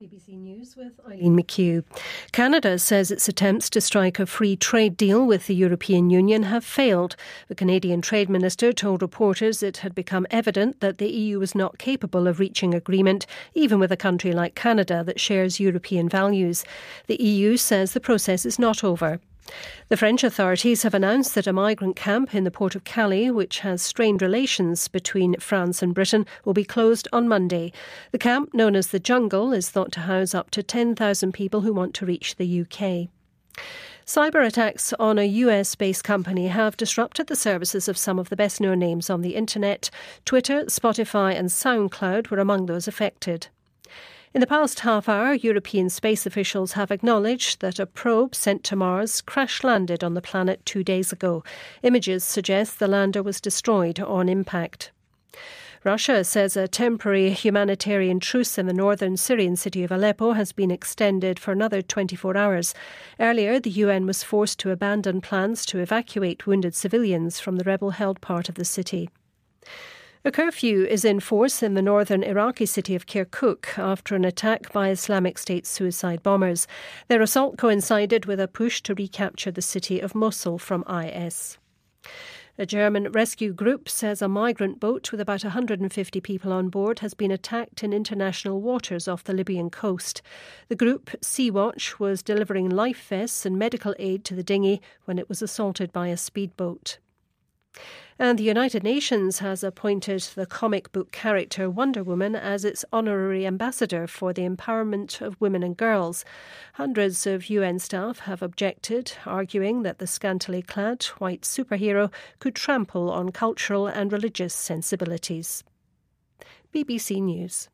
BBC news,“神奇女侠”成联合国女权大使